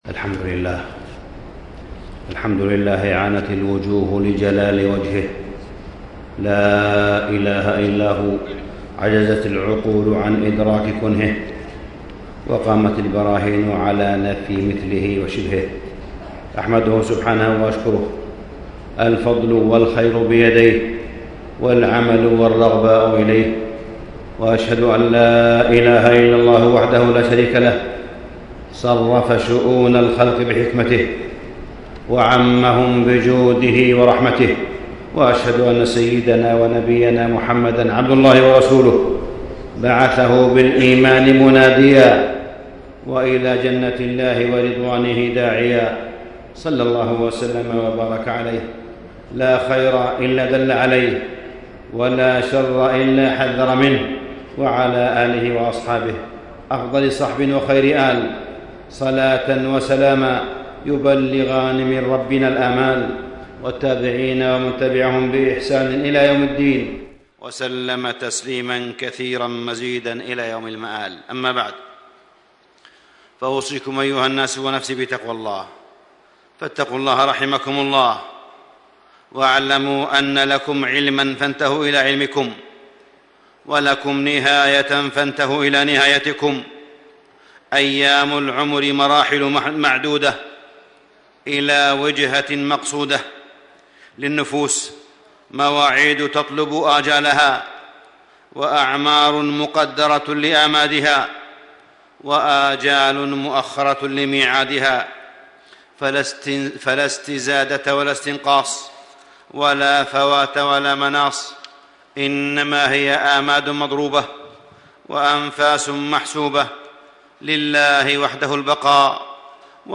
تاريخ النشر ٢١ ربيع الثاني ١٤٣٥ هـ المكان: المسجد الحرام الشيخ: معالي الشيخ أ.د. صالح بن عبدالله بن حميد معالي الشيخ أ.د. صالح بن عبدالله بن حميد الأسباب الجالبة للرزق